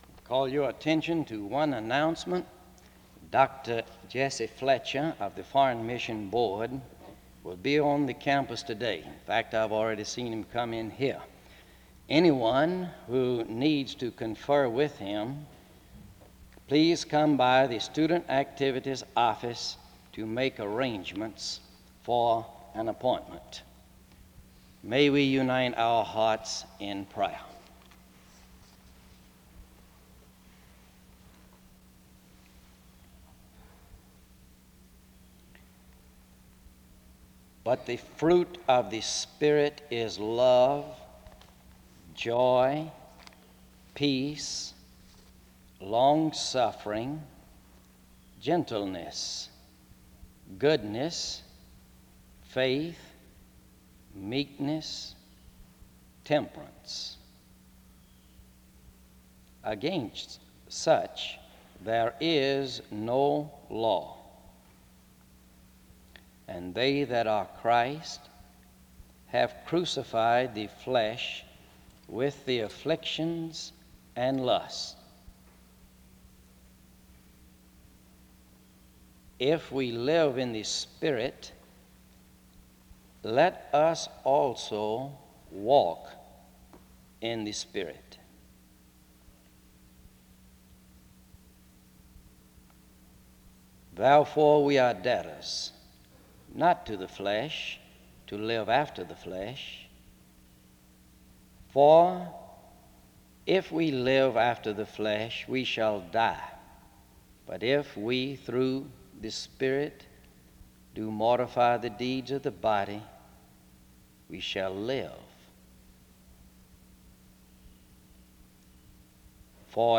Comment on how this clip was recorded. The service begins with announcements and the reading of selected verses in Galatians 5 from 0:00-2:40. Location Wake Forest (N.C.)